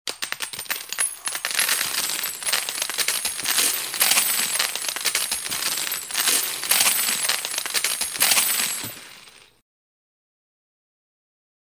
На этой странице собраны разнообразные звуки монет: от звонкого падения одиночной монеты до гула пересыпающихся денежных масс.
Шорох рассыпающихся монет по полу